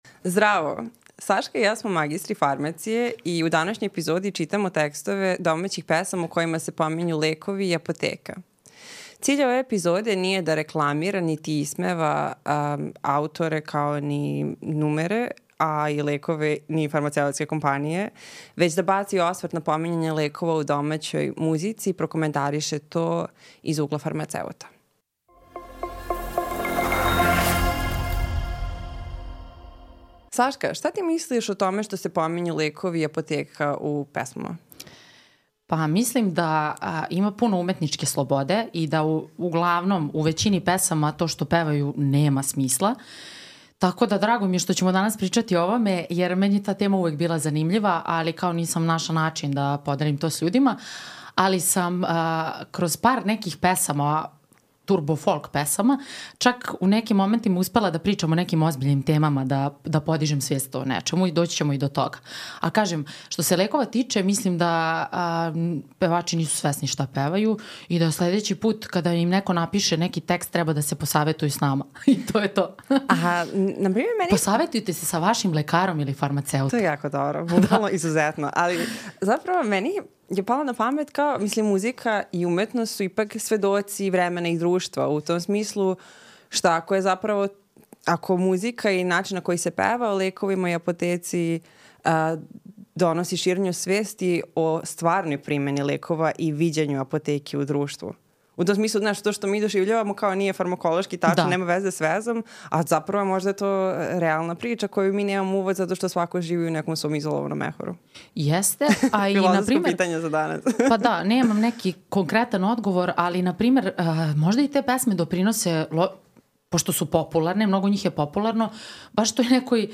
Podkast o tebi vode dve magistre farmacije i pričaju o njima bliskim temama za koje smatraju da su važne za opšte znanje svakog od nas.